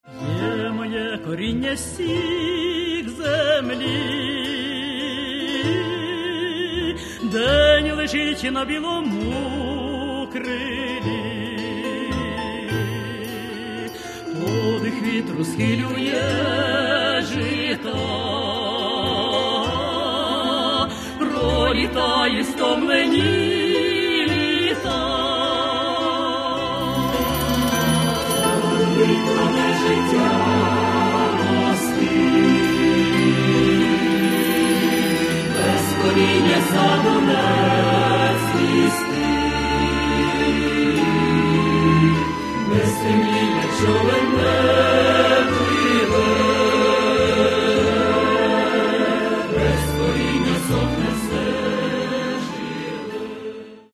Каталог -> Народная -> Ансамбли народной музыки